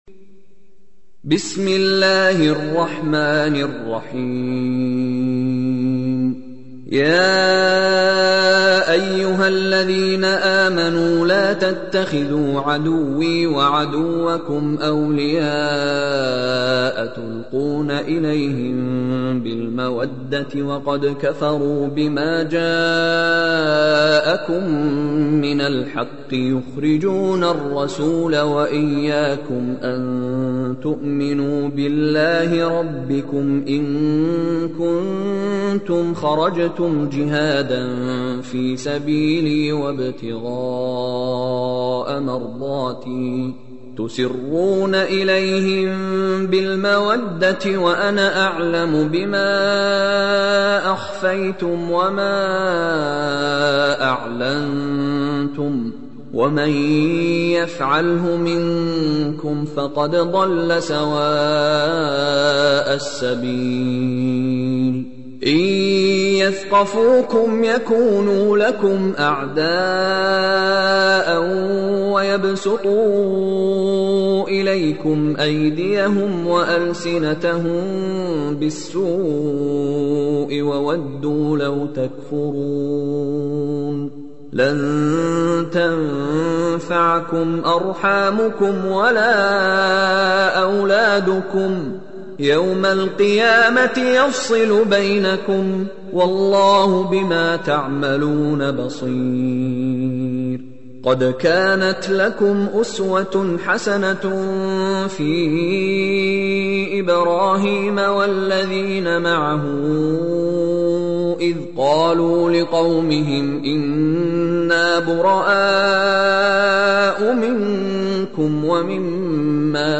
Чтение Корана > МИШАРИ РАШИД